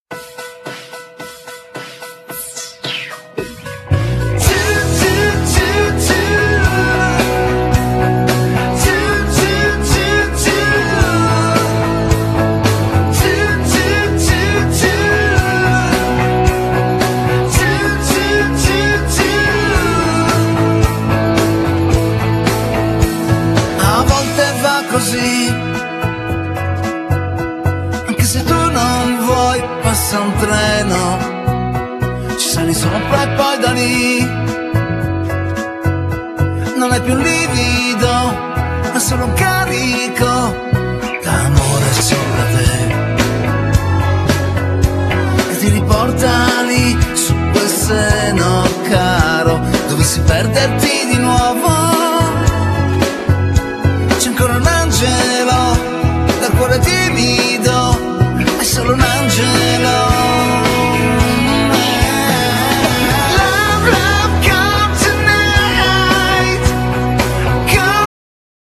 Genere: Pop